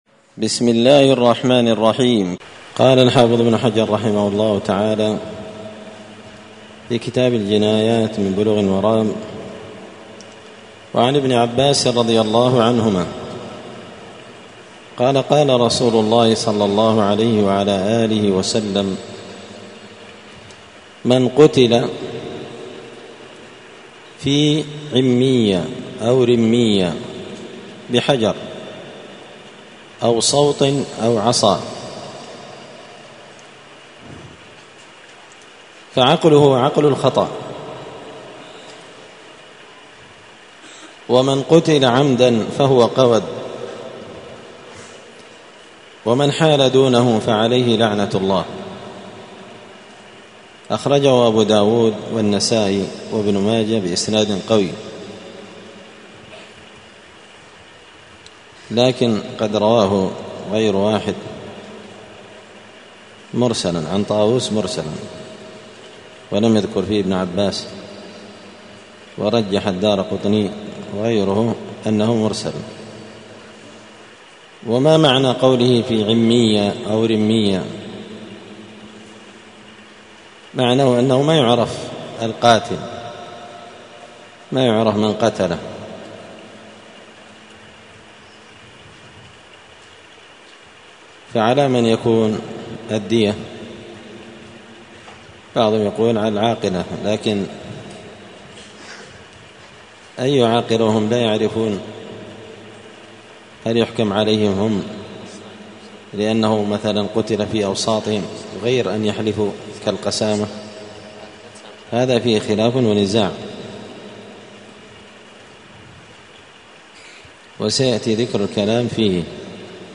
*الدرس الخامس عشر (15) {باب من لم يعرف قاتله}*